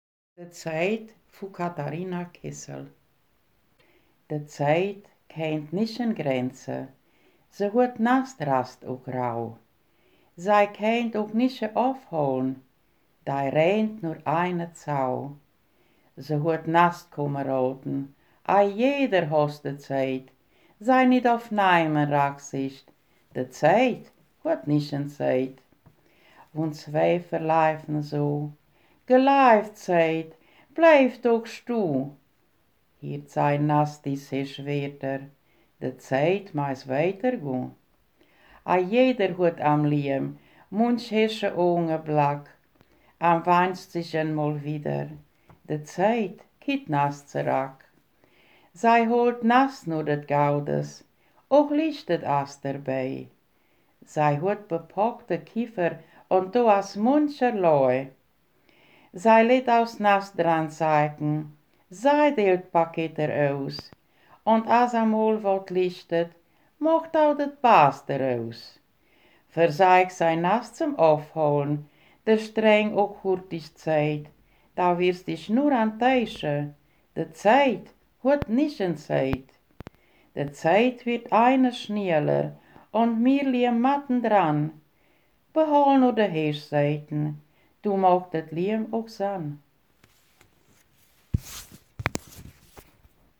Das Gedicht ist in der Ortsmundart von Paßbusch mit Weilauer Einschlag (beide Nordsiebenbürgen) geschrieben.
Ortsmundart: Paßbusch